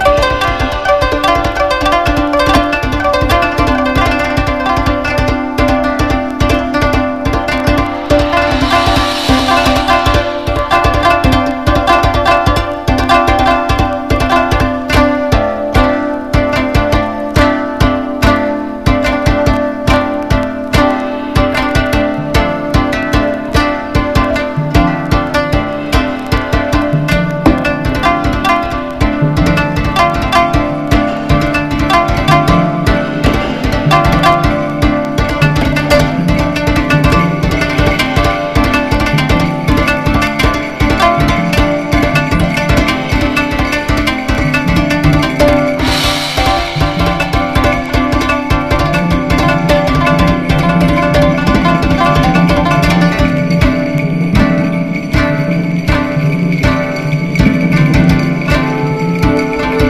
ユーロ・ジャズ的なコンテンポラリー・サウンドを聴かせます。